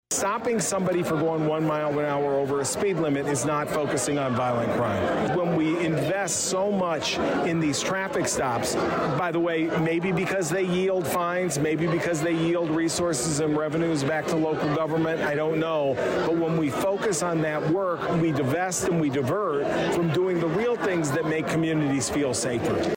A passionate crowd attended Saturday’s (March 4th) speech